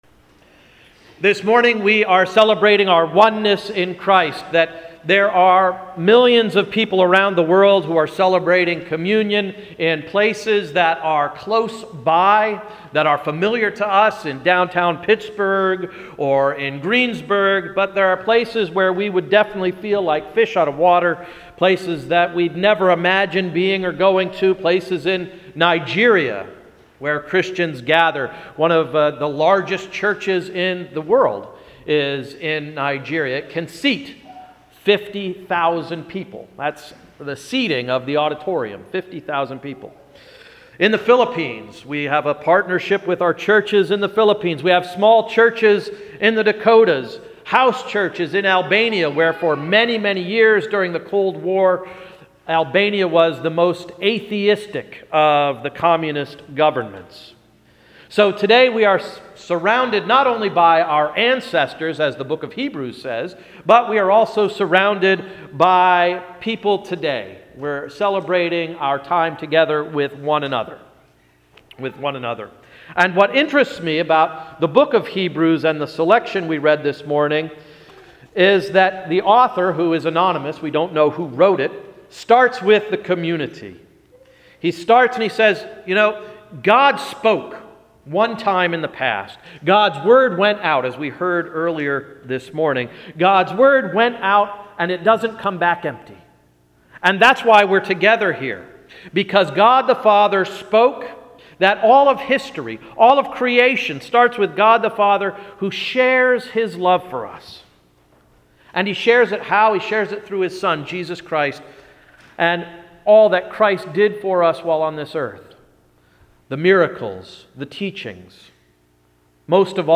Sermon of October 7, 2012–“Flesh and Blood” Worldwide Communion Sunday